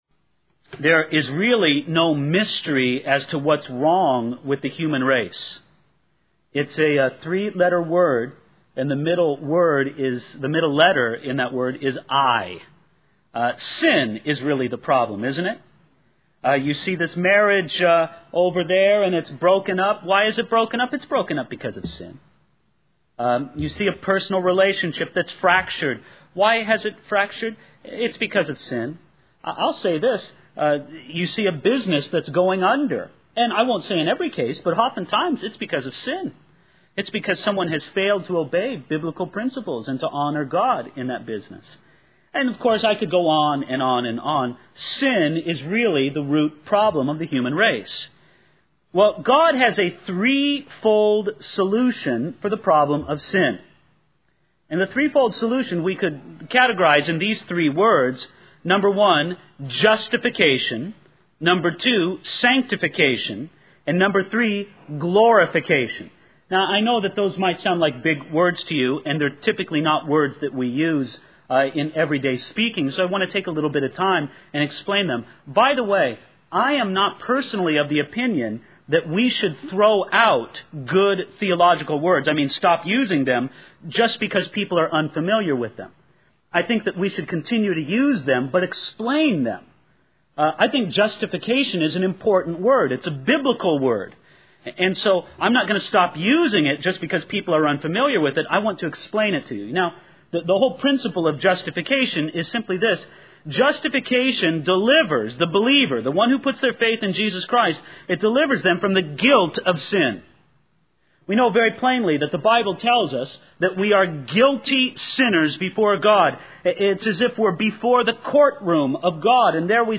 In this sermon, the speaker addresses the problem of sin as the root issue of the human race. He introduces a three-fold solution to this problem: justification, sanctification, and glorification. The speaker emphasizes the importance of using theological words like justification and explains its meaning as being delivered from the guilt of sin through faith in Jesus Christ.